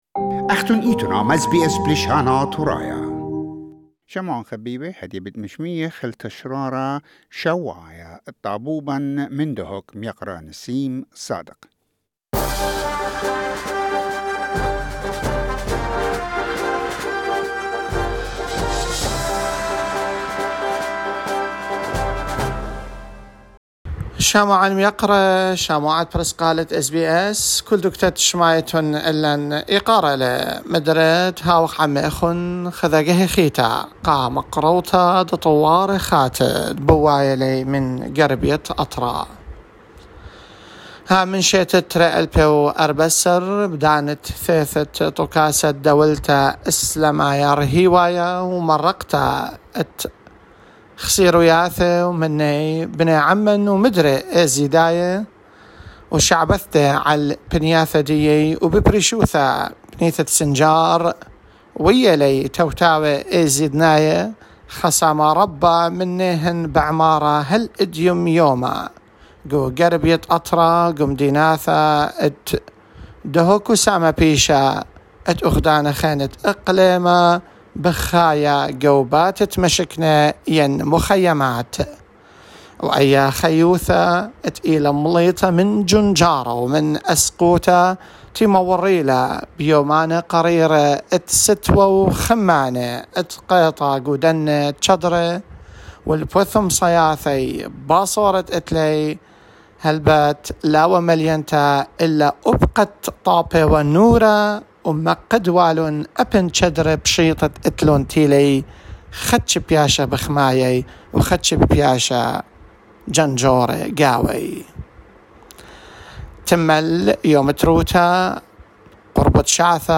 A Weekly report from Northern Iraq that brings us the latest developments from that region.